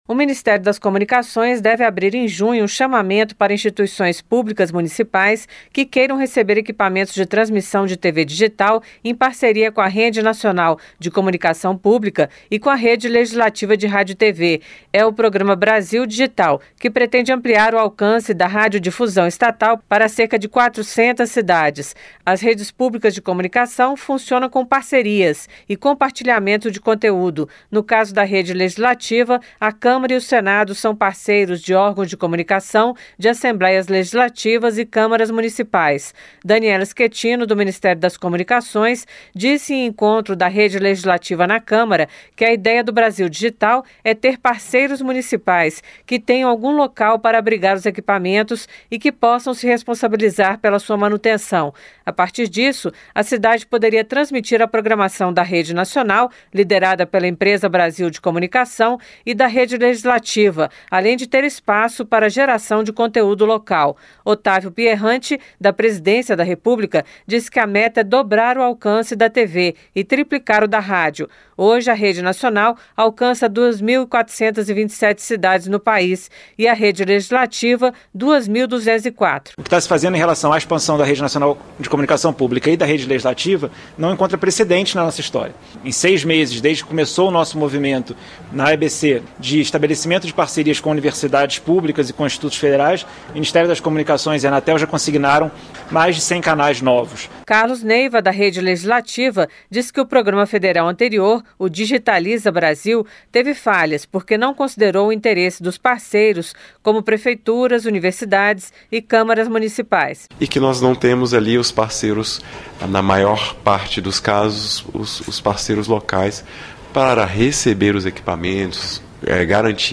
Governo vai abrir edital para que municípios recebam equipamentos de TV digital - Radioagência